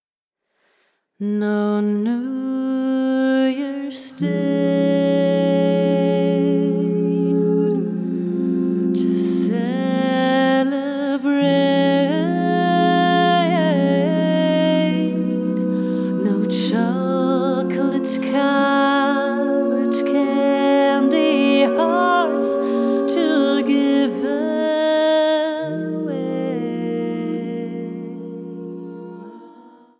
diesmal eine reine Jazz-Platte.